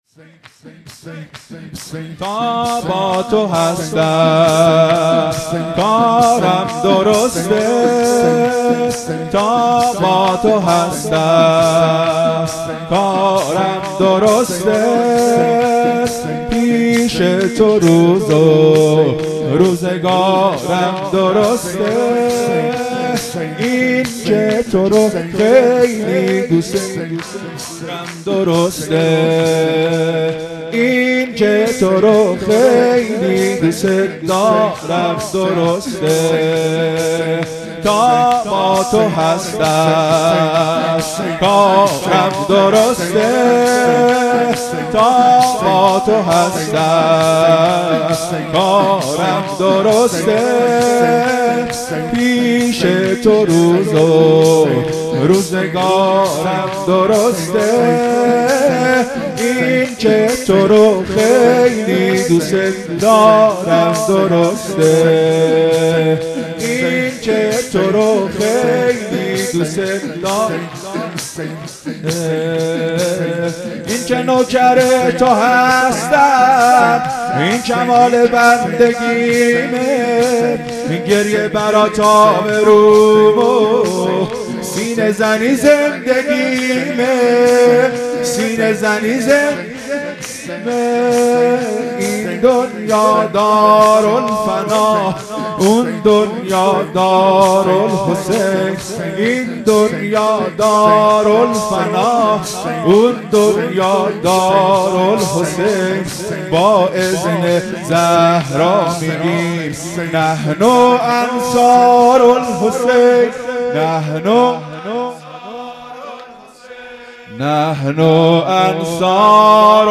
1 0 شور۱ | تا با تو هستم کارم درسته مداح